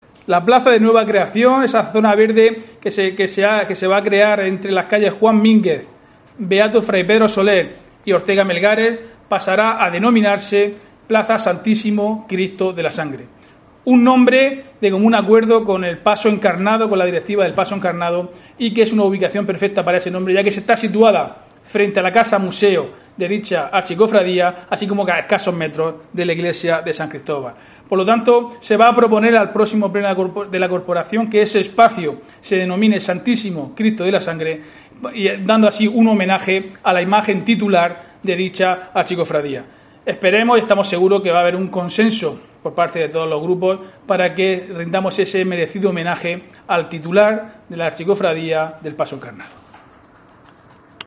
Diego José Mateos, alcalde de Lorca sobre nueva plaza